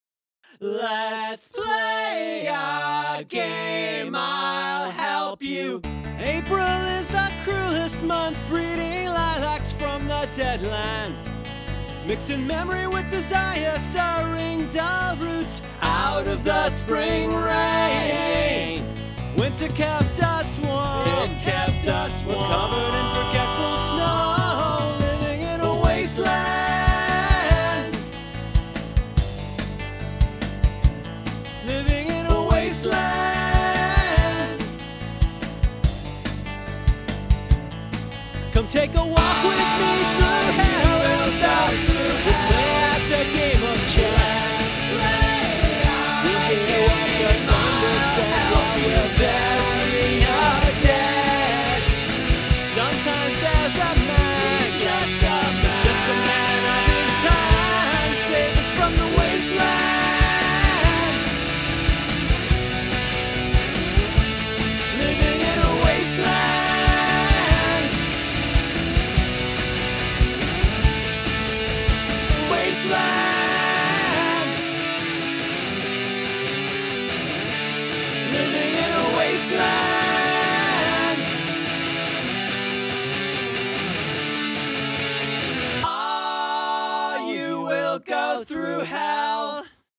In college, I wrote a terrible throwaway novelty song nominally based on T.S. Eliot’s The Waste Land.